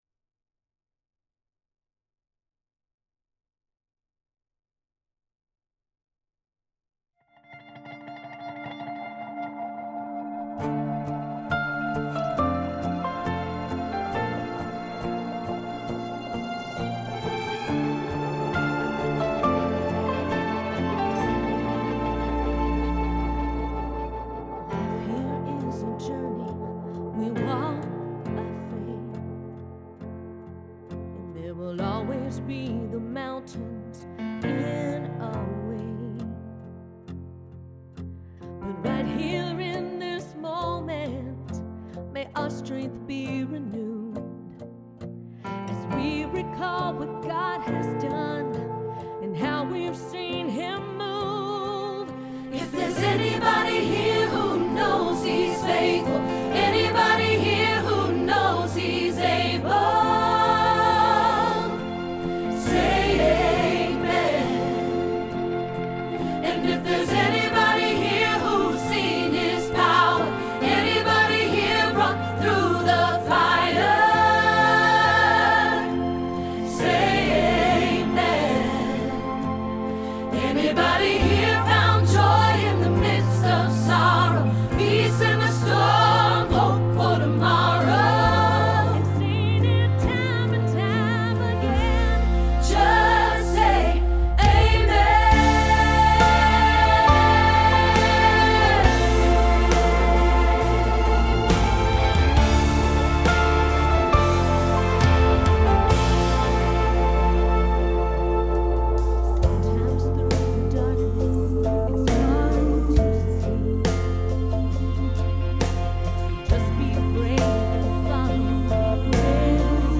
Say Amen – Soprano Hilltop Choir